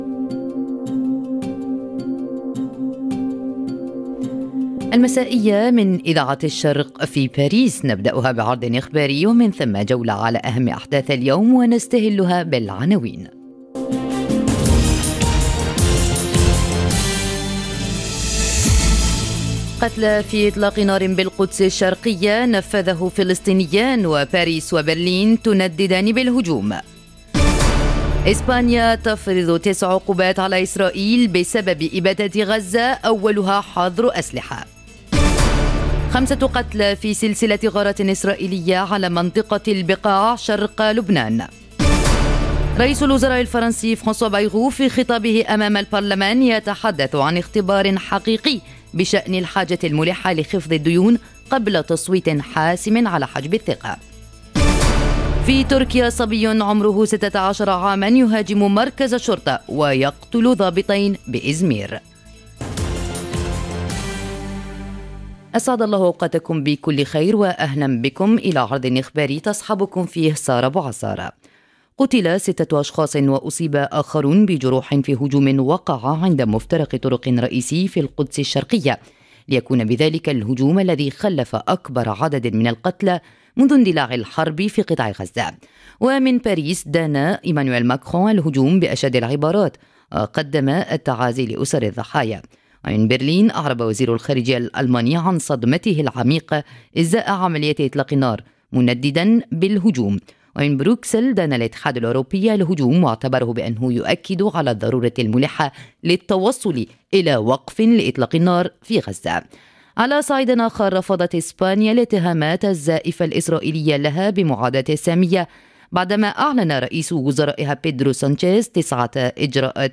نشرة أخبار المساء: تصعيد في القدس وغزة ولبنان، ضغوط أوروبية على إسرائيل، وأزمات سياسية وأمنية في فرنسا وتركيا - Radio ORIENT، إذاعة الشرق من باريس